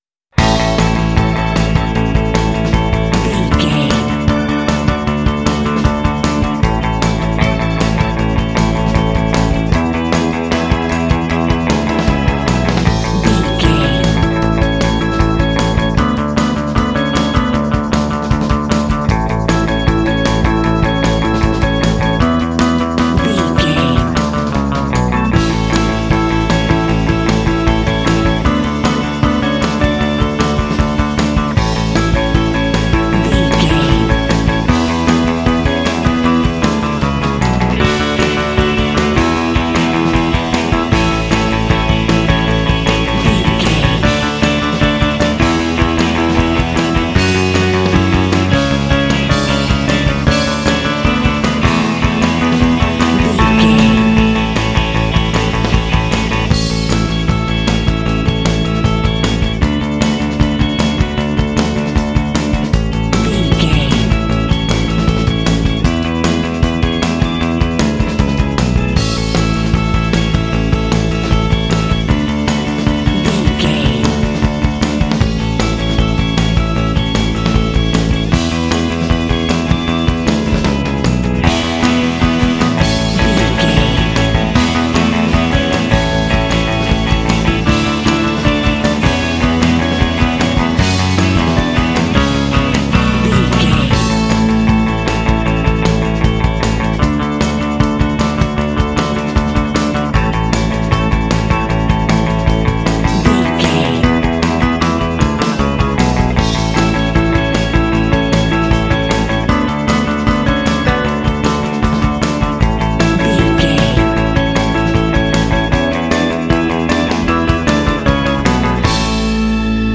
Uplifting
Ionian/Major
energetic
joyful
electric organ
bass guitar
electric guitar
drums
alternative rock